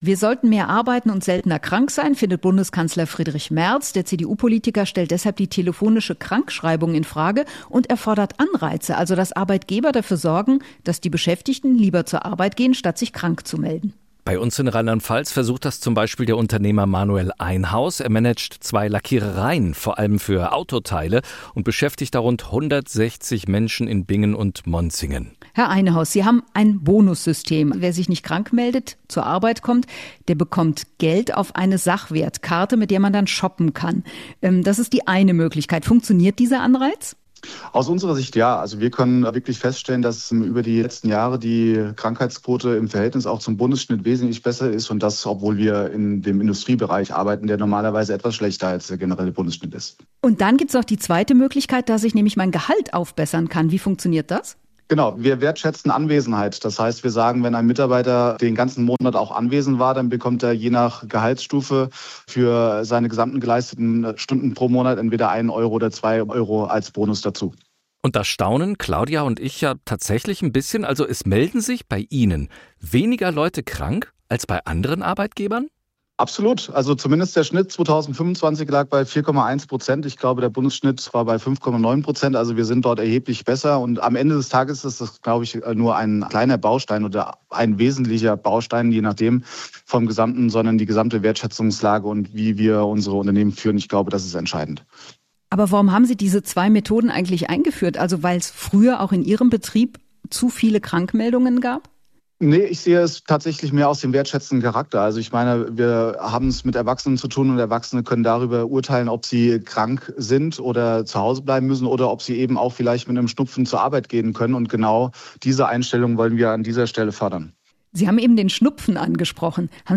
Mehr SWR1 Interviews